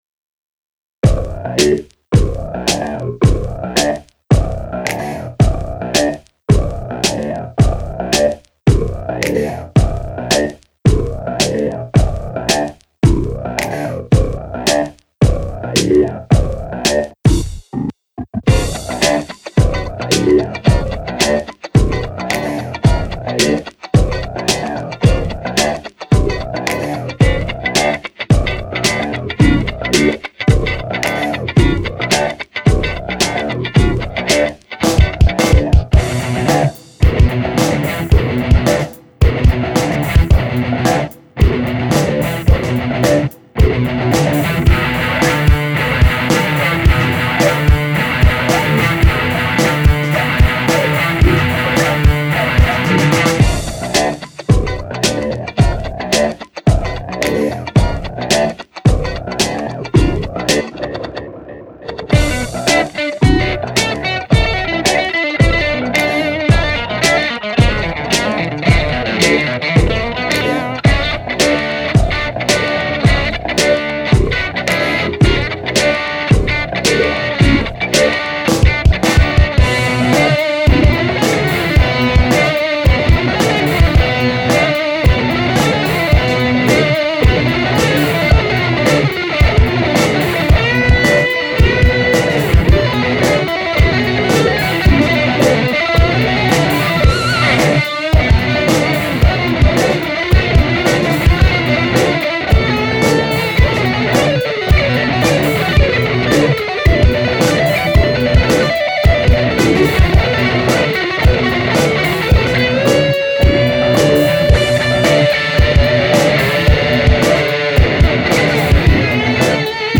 A nahravka sa mi tiez paci, dobre si sa pohral s panoramou v tom sole.
naozaj pekne solo, velmi velmi sa mi paci... ale skoda je, ze sa mi paci len to solo, ale inac respekt  Na zdravie!
Niektoré prvky sóla sú fajn, potom je to už taká gitarová rozcvička..
ale fajn..solicke je fajne aj divoke.. podklad nie prilis podla mojho vkusu. mozno by som dal solo "malililililililinko" viac dopredu
tak solicko bolo fakt ze dobre, velmi sa mi to pacilo, mne nejak nesedel ten podmaz, ten mi bol nejak proti srsti, ale inak pekna ukazka Na zdravie!
A onania je skvelá, milujem tieto hendrixovské onanie :) A má to aj vtipný záver, len ten delay mi tam nesedí.